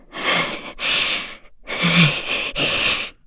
FreyB-Vox_Sad2_kr.wav